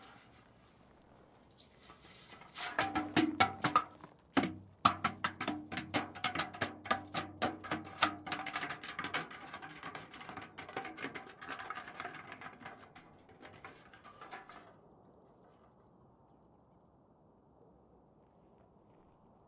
机器 " 金属桶轧制
描述：金属桶滚动